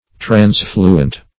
Search Result for " transfluent" : The Collaborative International Dictionary of English v.0.48: Transfluent \Trans"flu*ent\, a. [Pref. trans- + fluent.] 1.